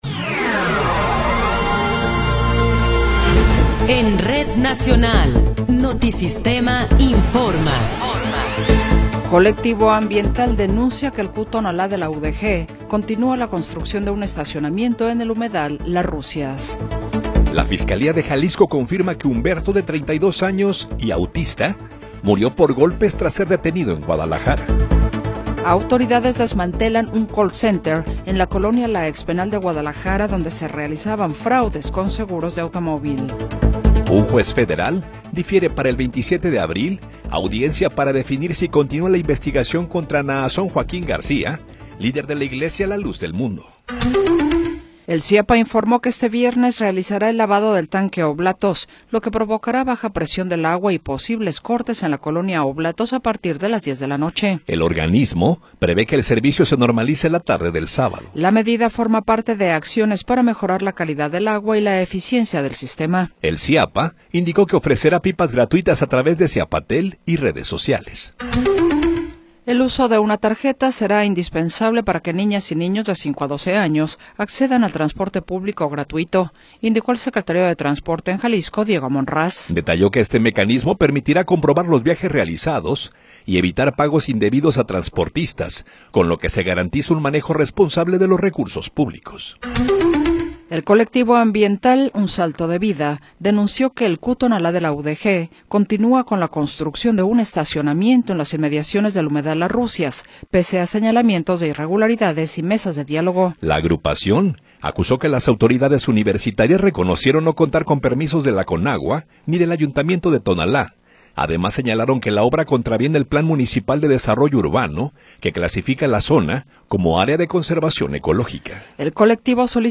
Noticiero 20 hrs. – 16 de Abril de 2026
Resumen informativo Notisistema, la mejor y más completa información cada hora en la hora.